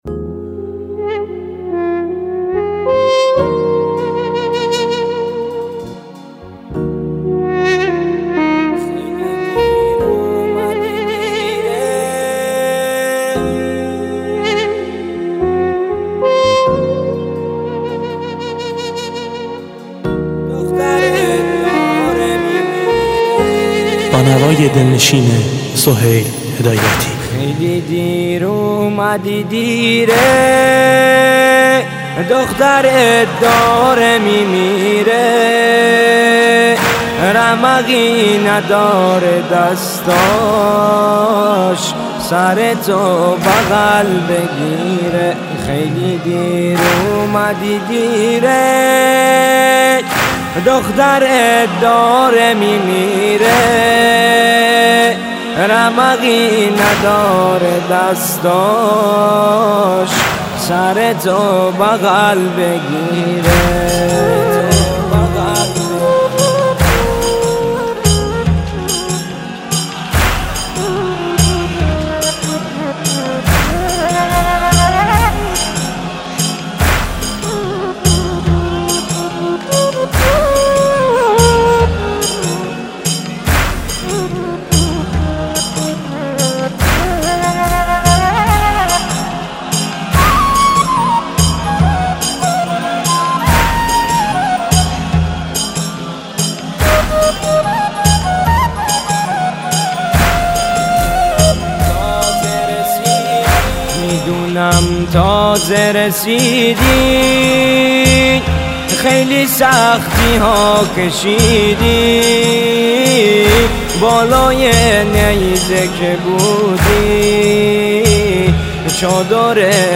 مداحی مازندرانی